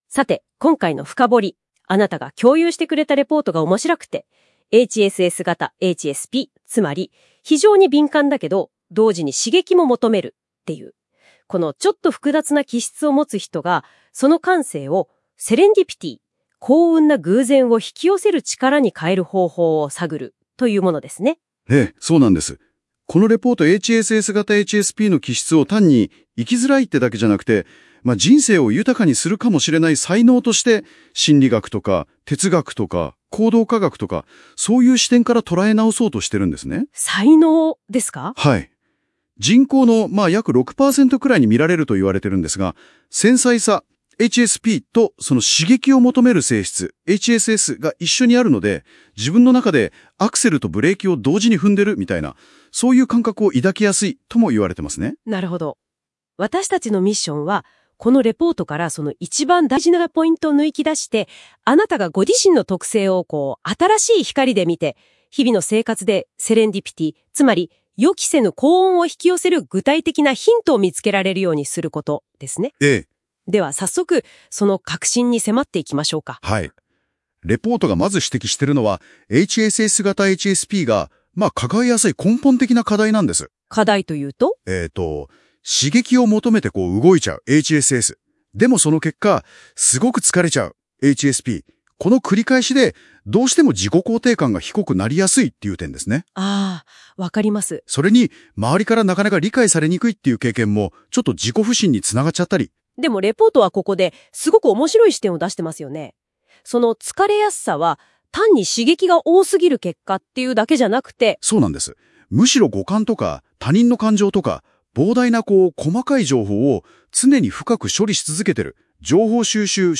【音声解説】HSS型HSPの「疲れ」を「才能」に変える：セレンディピティを引き寄せる3ステップと自己肯定感の育て方